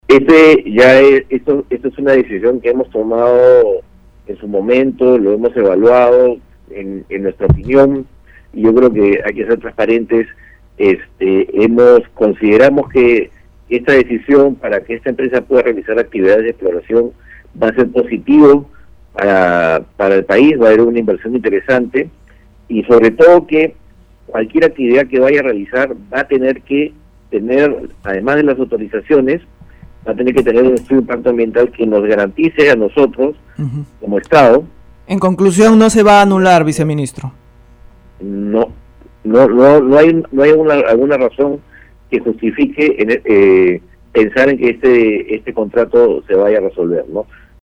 Declaraciones del viceministro de hidrocarburos, Eduardo Guevara Agregó que para la concesión de los próximos lotes petroleros en las otras regiones se tomará en cuenta la opinión de la población.